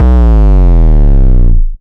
Hard 808 Sweep Down.wav